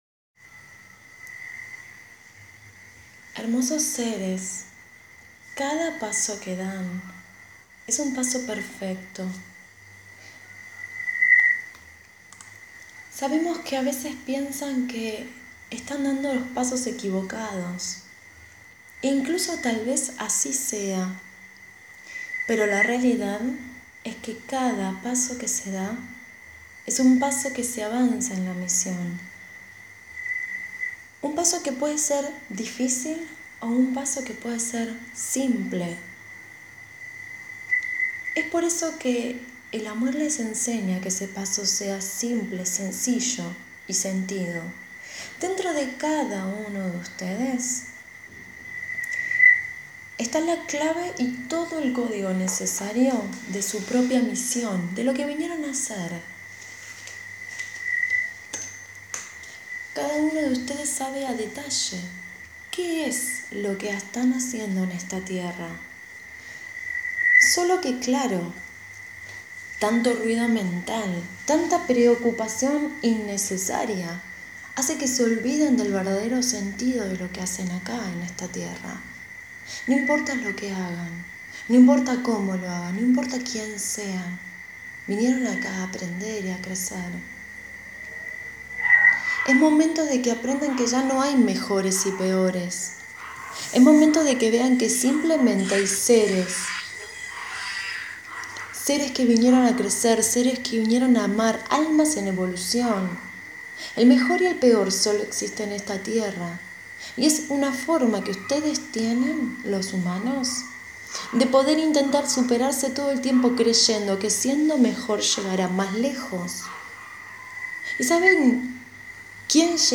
Este mensaje fue dado a los participantes de la charla de Sanación y Símbolos Arcturianos y meditación gratuita el día Lunes 18/01/2016.